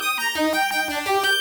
Index of /musicradar/shimmer-and-sparkle-samples/170bpm
SaS_Arp03_170-C.wav